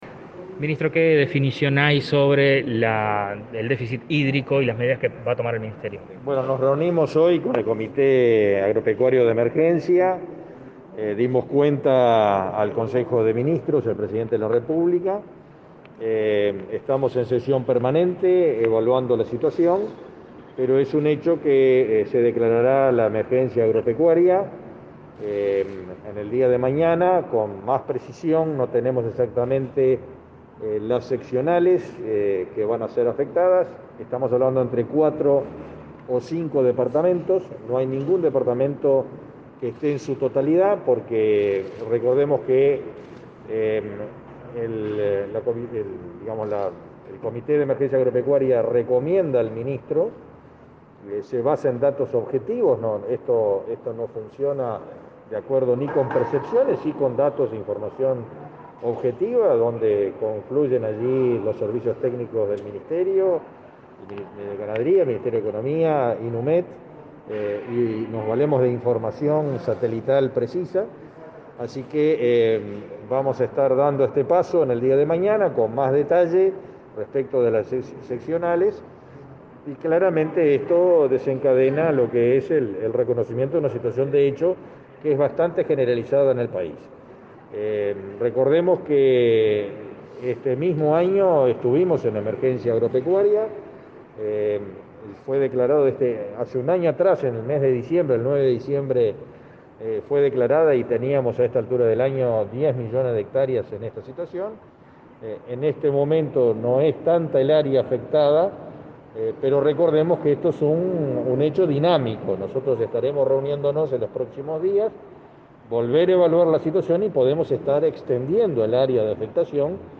Declaraciones a la prensa del ministro de Ganadería, Fernando Mattos